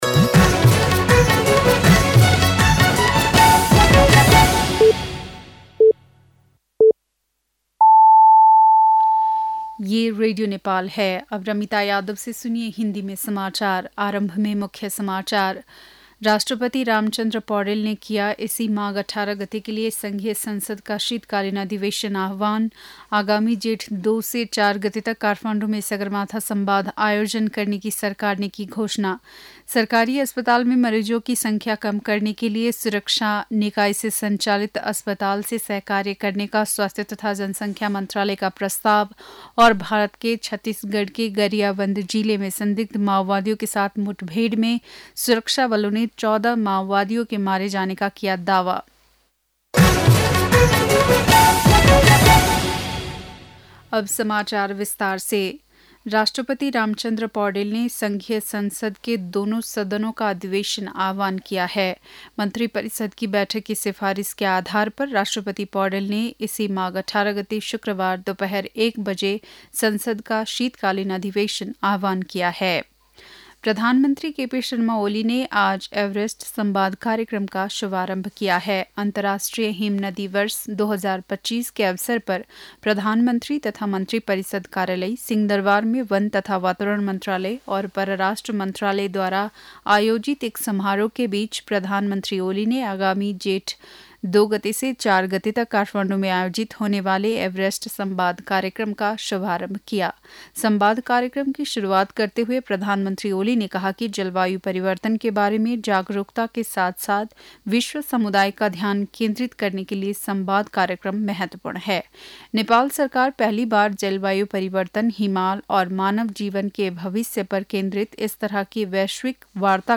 बेलुकी १० बजेको हिन्दी समाचार : ९ माघ , २०८१